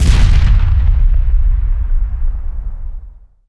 explode2.wav